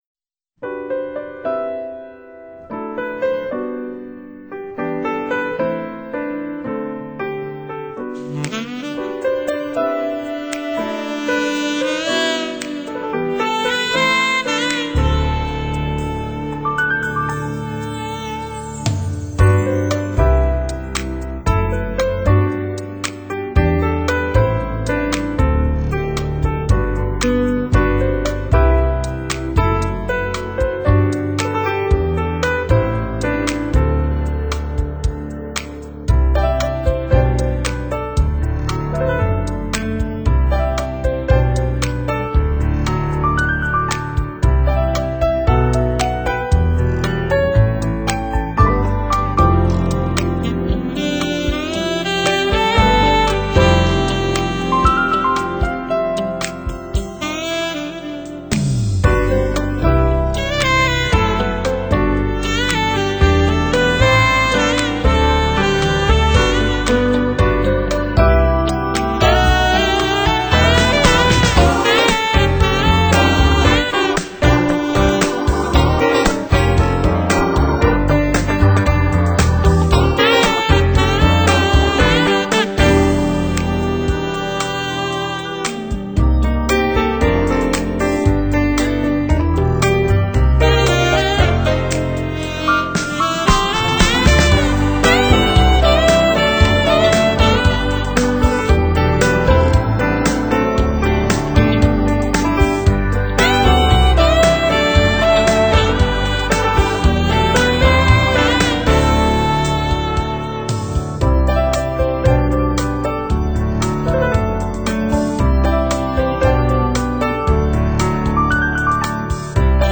此外還有一整隊貝斯，鼓，號，吉他及打擊樂器。